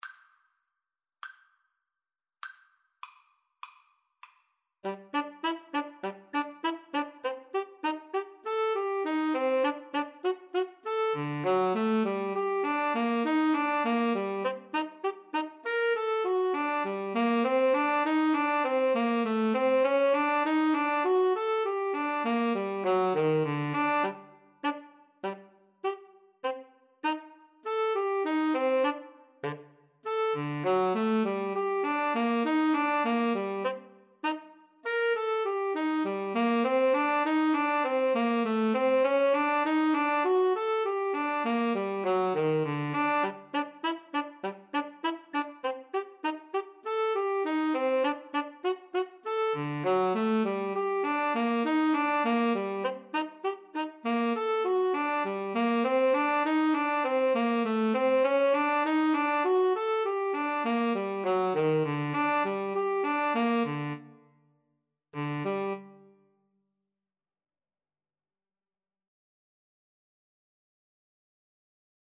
4/4 (View more 4/4 Music)
Jazz (View more Jazz Alto-Tenor-Sax Duet Music)